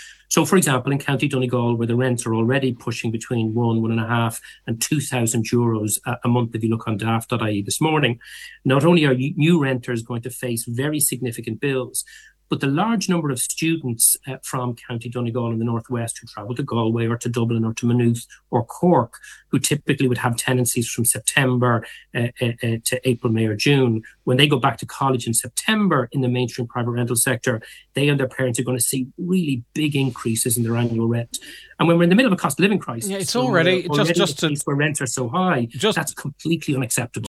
Housing spokesperson Eoin O’Broin said students will face increased financial pressure as a result: